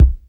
just blaze klick kick.WAV